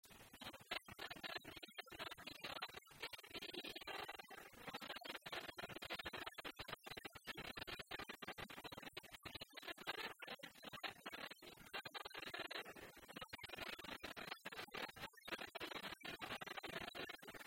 Localisation Bois-de-Céné
Genre laisse
Catégorie Pièce musicale inédite